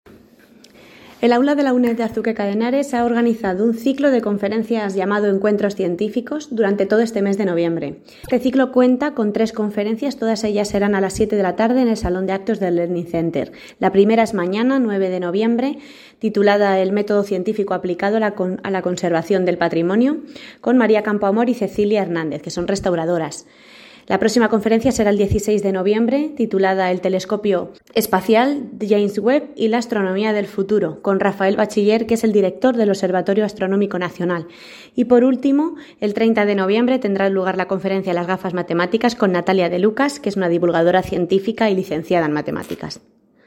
Declaraciones de la concejala Susana Santiago